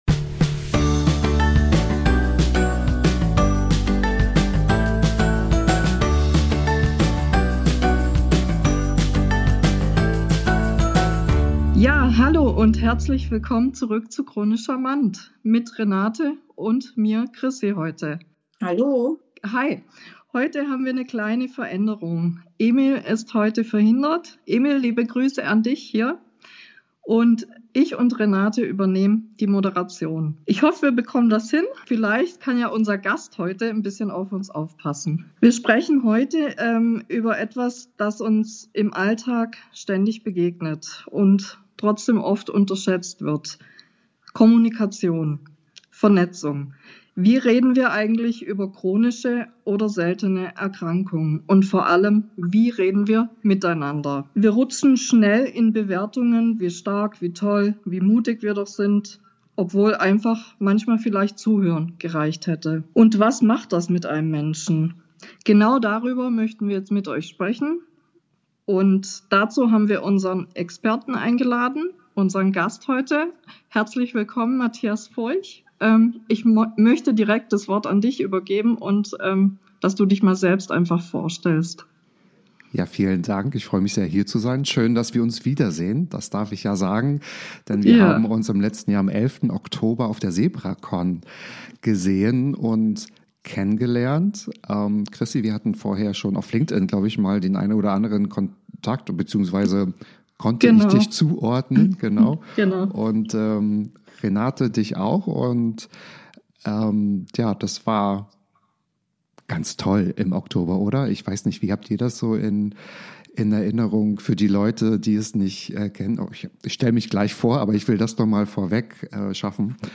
Folge 9: Vernetzen statt verzweifeln — Expertentalk